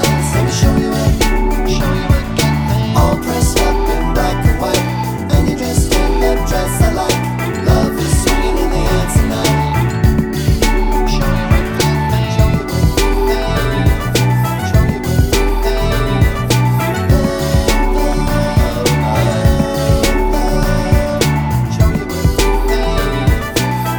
no Backing Vocals R'n'B / Hip Hop 4:32 Buy £1.50